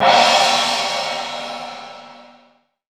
Index of /90_sSampleCDs/300 Drum Machines/Akai MPC-500/3. Perc/ChinesePrc